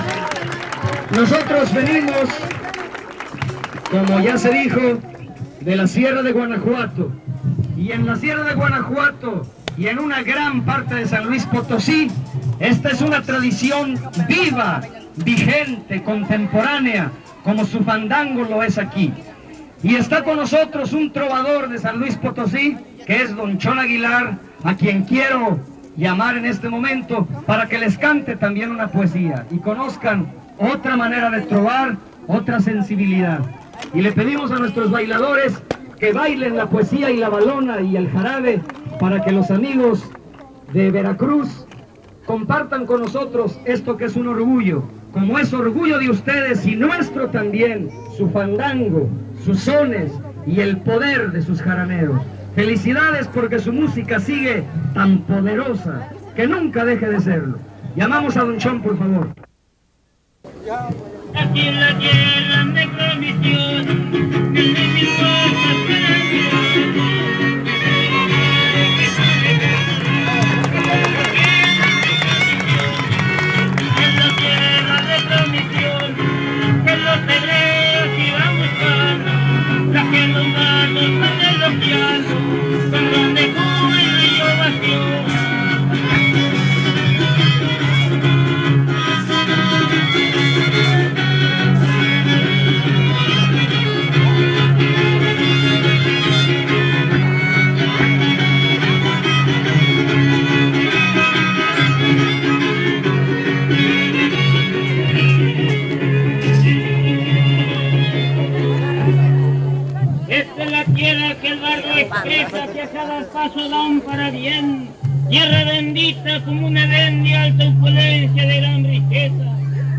Encuentro de son y huapango